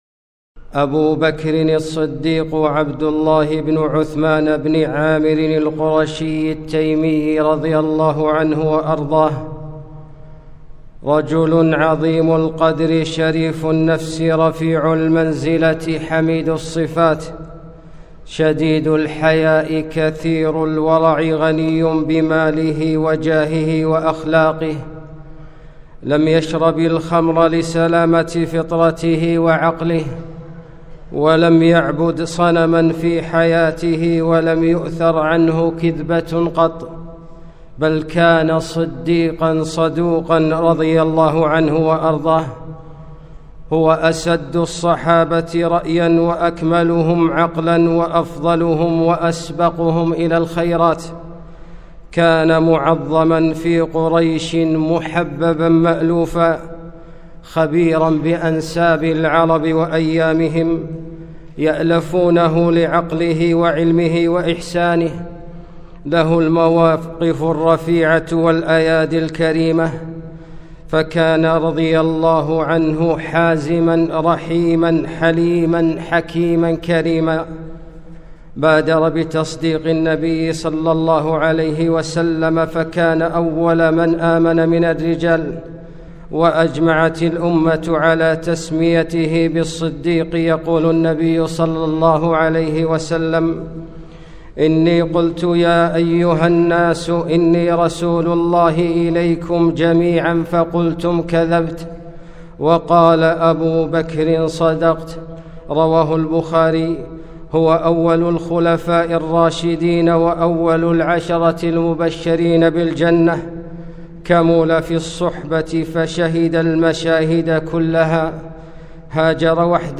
خطبة - صاحبُ رسول الله